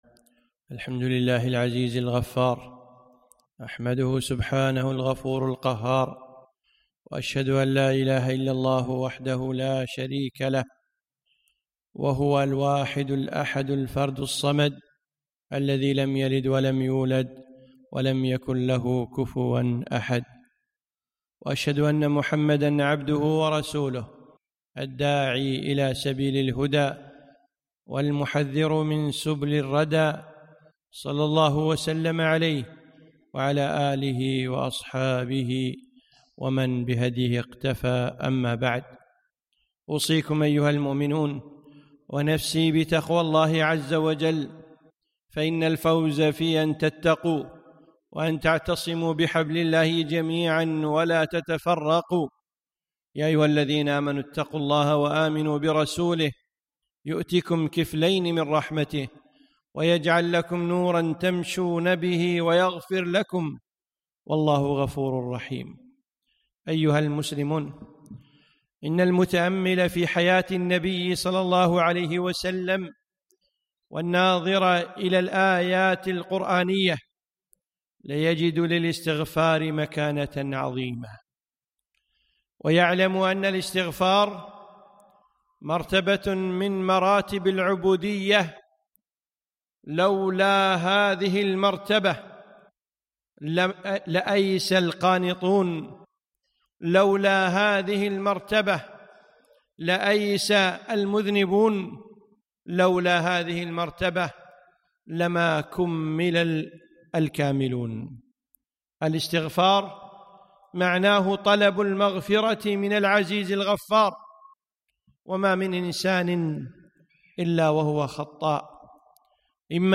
خطبة - الاستغفار